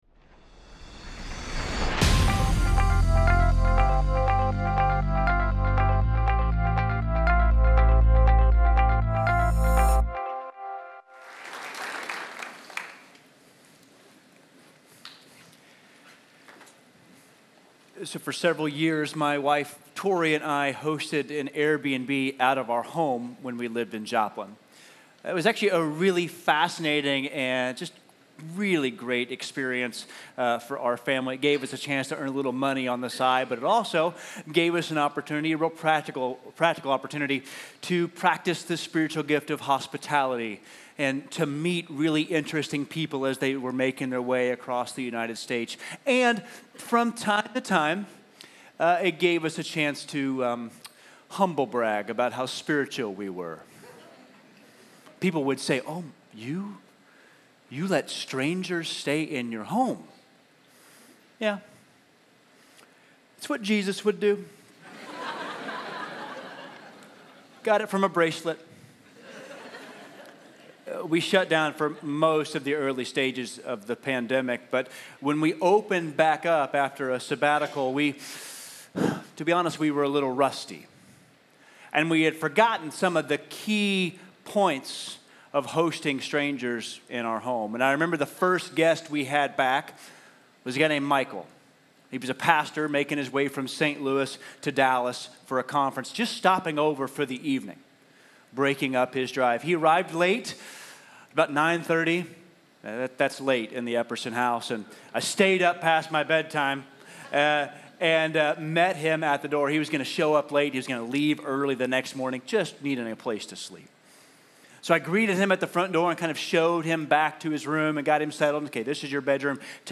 Keep the Door Open | Guest Speaker